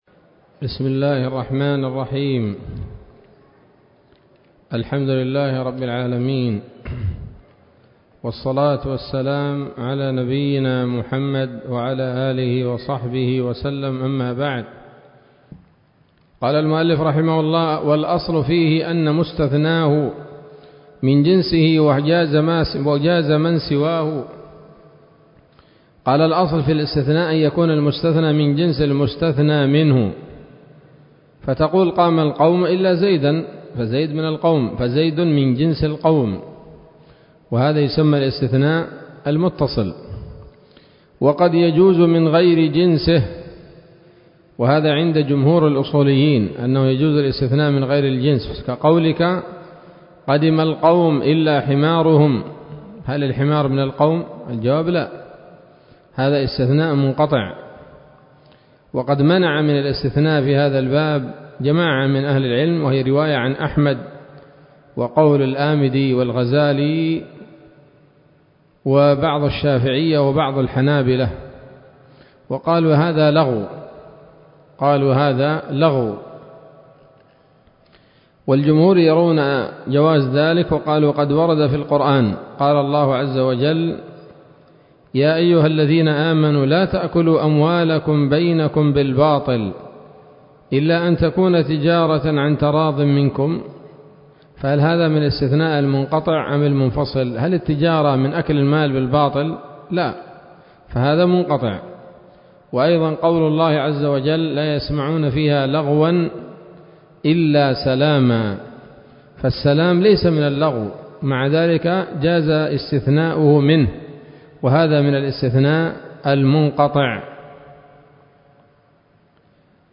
الدرس الثاني والأربعون من شرح نظم الورقات للعلامة العثيمين رحمه الله تعالى